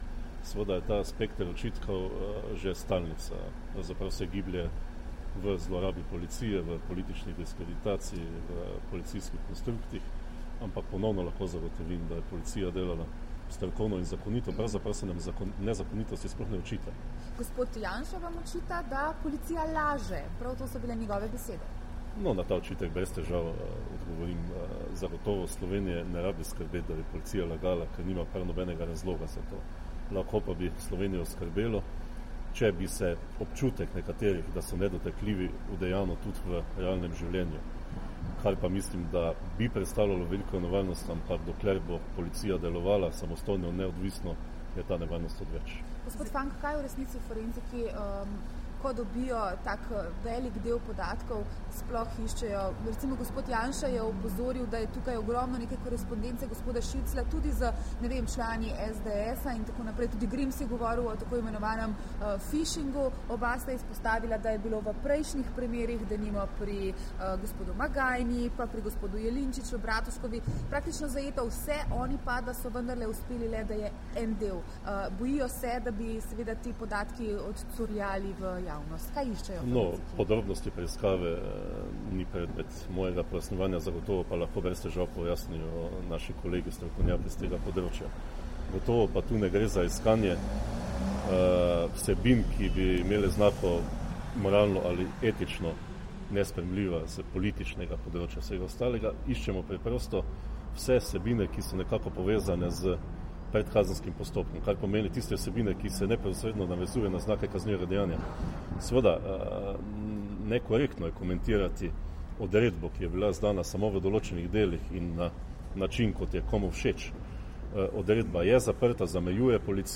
V povezavi s preiskavo Nacionalnega preiskovalnega urada in odzivom, ki ga je policija objavila včeraj, je generalni direktor policije Marjan Fank danes, 16. decembra 2015, podal še izjavo za medije.
Zvočni posnetek izjave generalnega direktorja policije Marjana Fanka in odgovori na vprašanja novinarjev